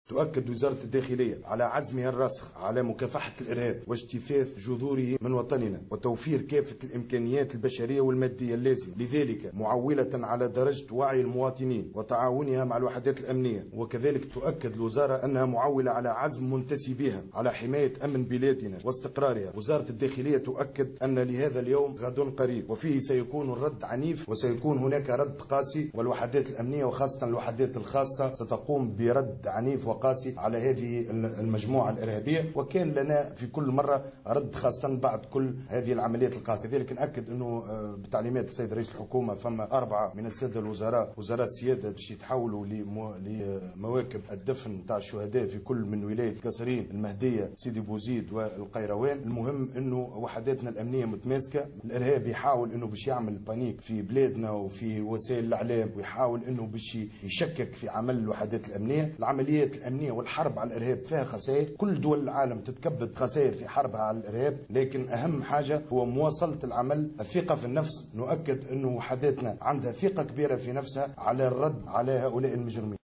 خلال اللقاء الإعلامي الذي عقدته الوزارة